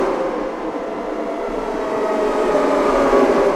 metro-sfx.mp3